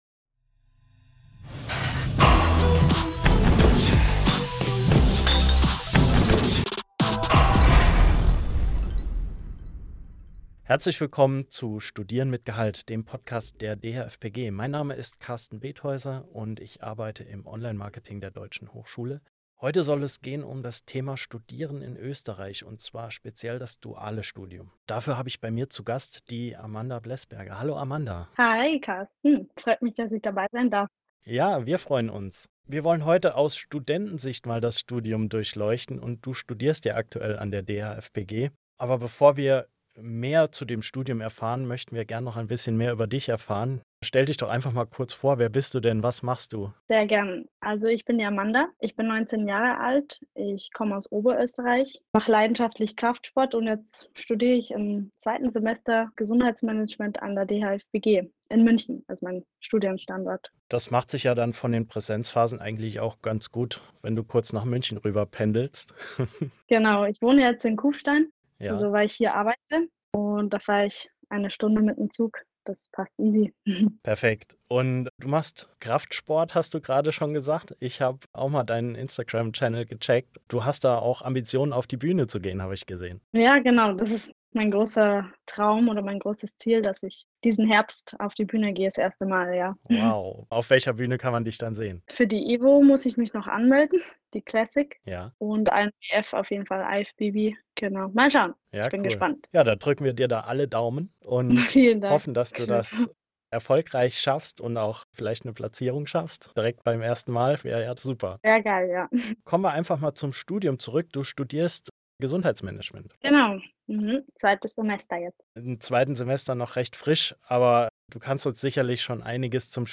Dual studieren in Österreich - Bachelor-Studentin im Interview ~ Studieren mit Gehalt Podcast